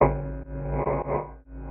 Destroy - LoopWatchPerc.wav